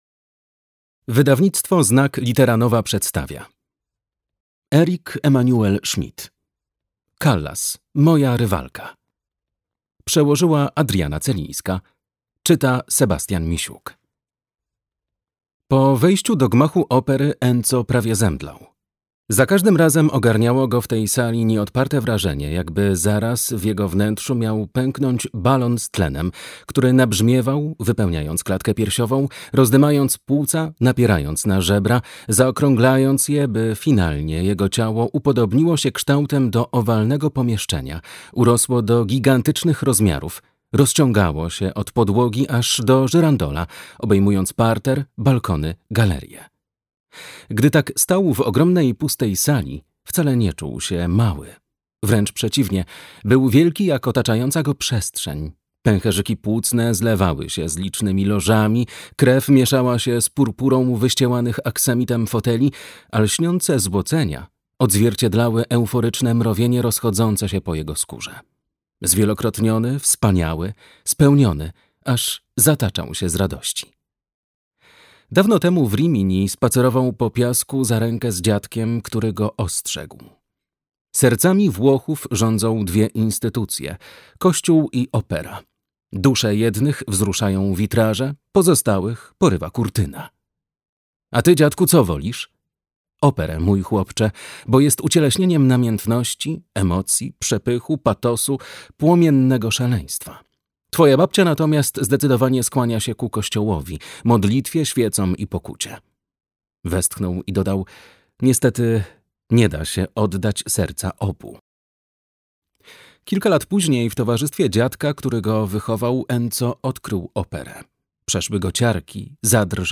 Callas, moja rywalka - Eric-Emmanuel Schmitt - audiobook